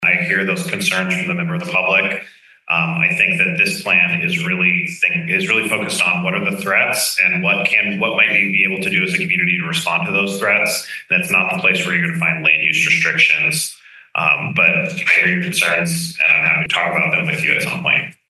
County Commissioner Mike French addressed those concerns before the vote.